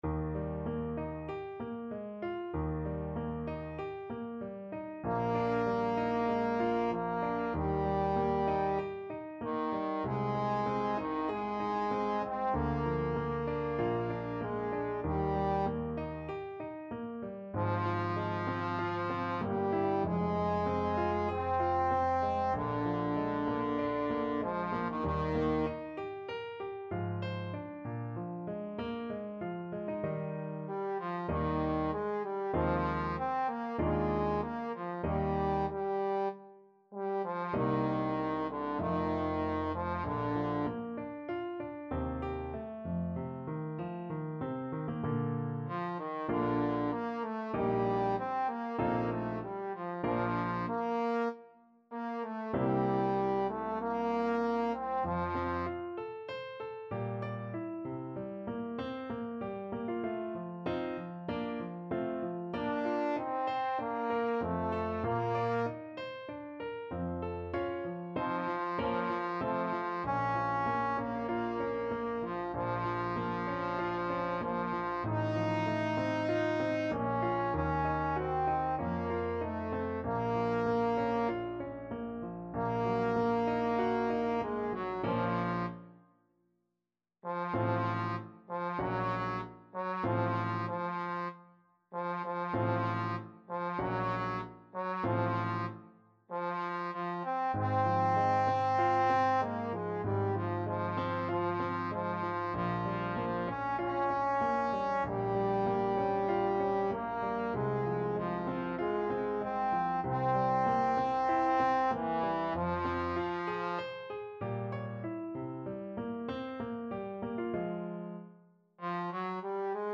Trombone
Eb major (Sounding Pitch) (View more Eb major Music for Trombone )
4/4 (View more 4/4 Music)
~ = 96 Andante
D4-Eb5
Classical (View more Classical Trombone Music)